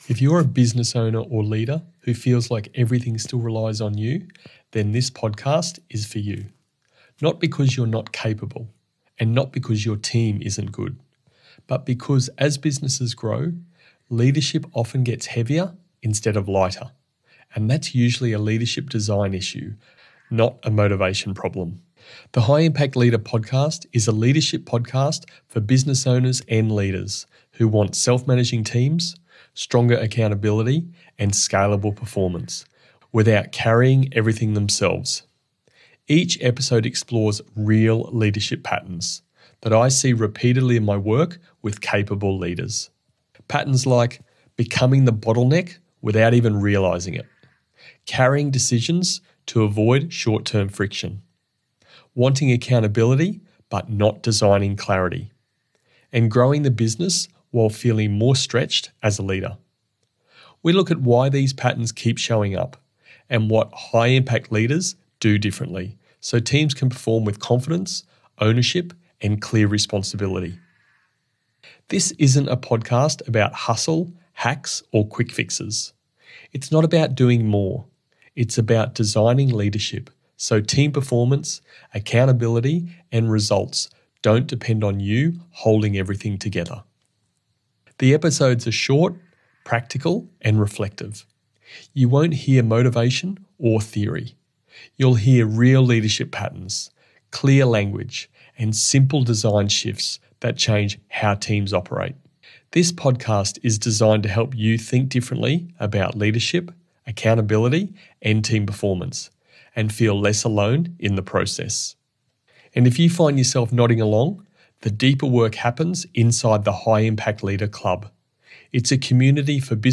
This trailer episode is for business owners and leaders who feel like everything still relies on them, and want self-managing teams, stronger accountability, and scalable performance without leadership feeling heavier as the business grows.